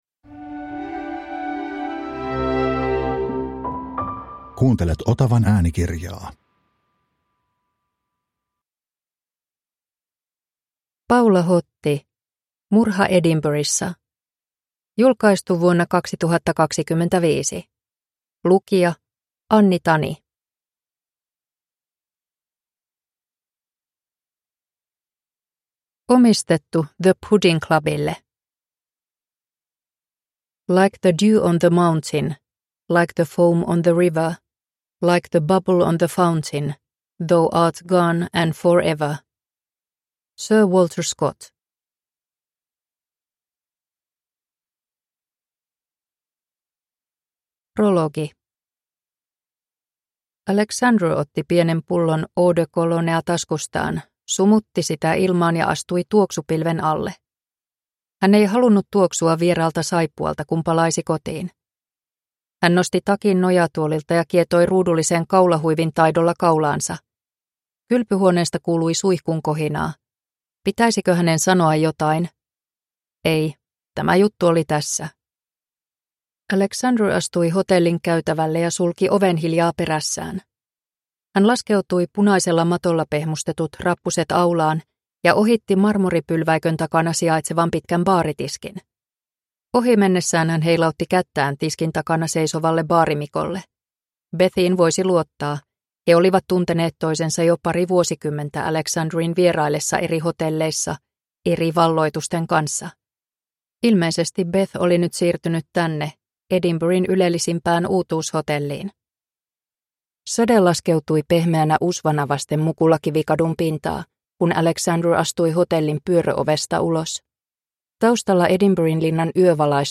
Murha Edinburghissa – Ljudbok